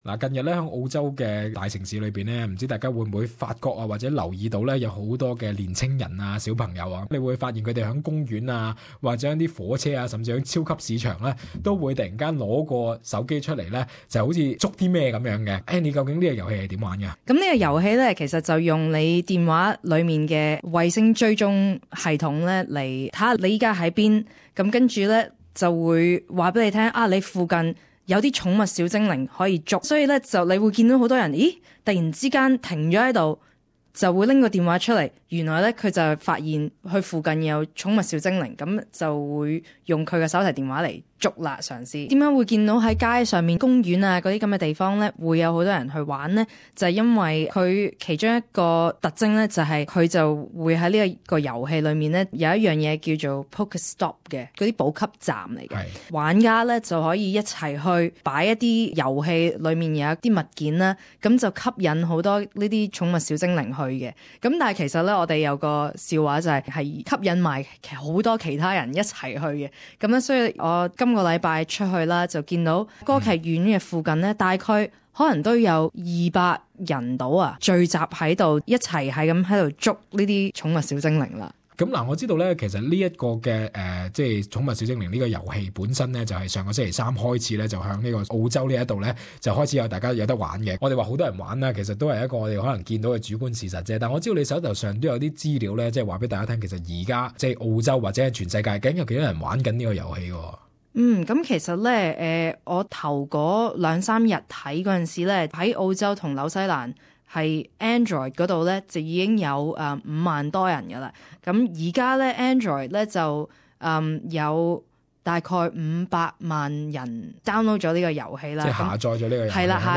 訪問:上架一週五百萬次下載|手機遊戲成全球熱話